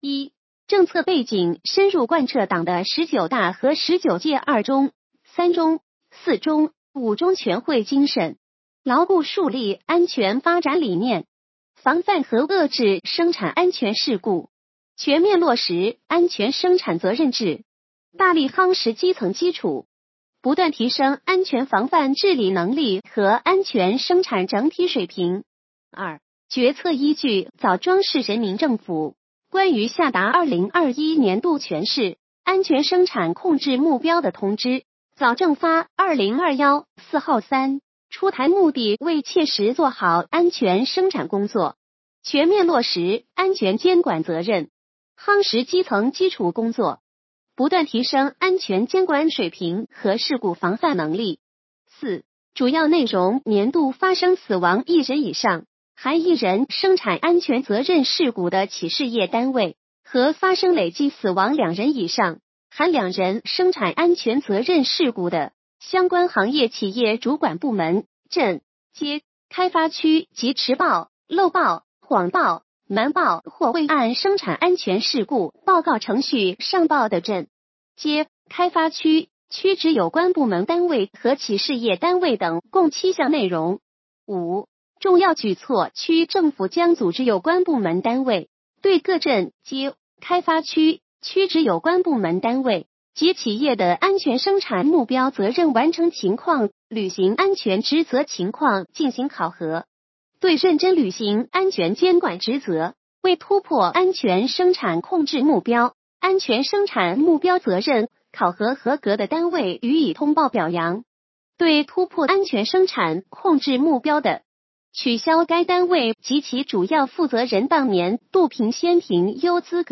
语音解读：山亭区人民政府关于下达2021年度全区安全生产控制目标的通知